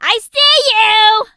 colette_start_vo_01.ogg